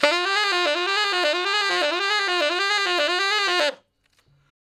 066 Ten Sax Straight (D) 41.wav